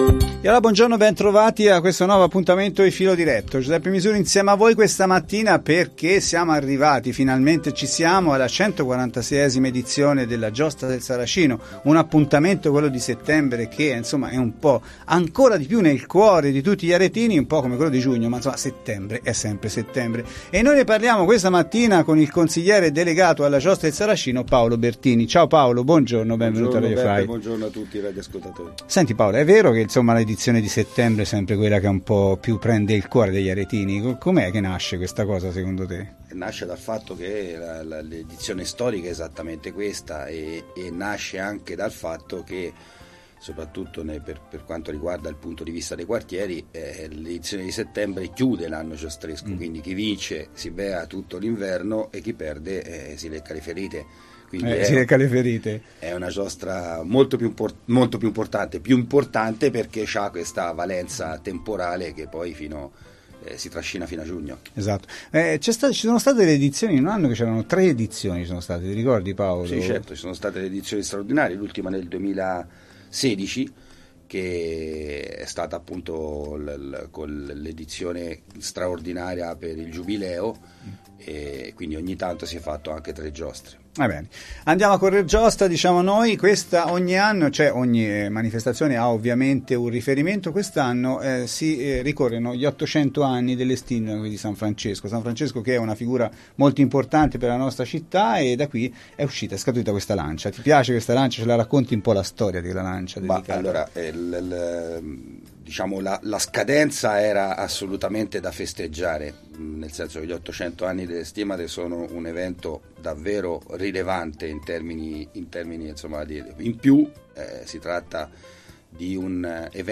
Ce ne parla quindi in studio, il Consigliere delegato alla Giostra Paolo Bertini.